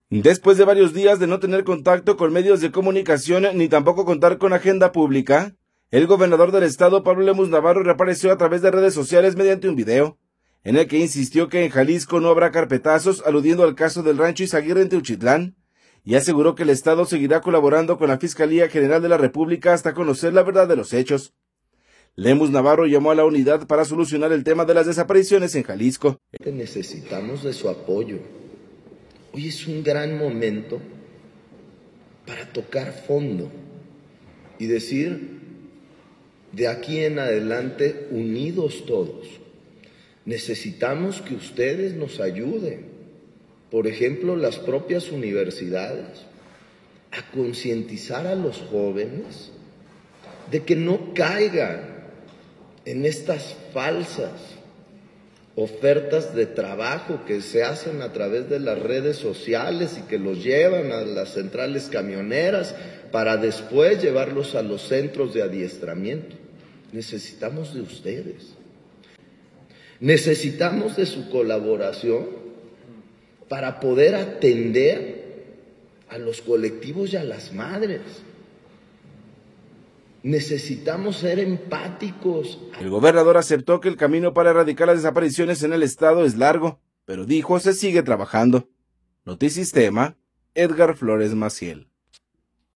Después de varios días de no tener contacto con medios de comunicación ni tampoco contar con agenda pública, el gobernador del estado, Pablo Lemus Navarro reapareció a través de redes sociales en un video, en el que insistió que en Jalisco no habrá carpetazos, aludiendo al caso del rancho izaguirre en Teuchitlán, y aseguró que el estado seguirá colaborando con la Fiscalía General de la República hasta conocer la verdad de los hechos. Lemus Navarro llamó a la unidad para solucionar el tema de las desapariciones en Jalisco.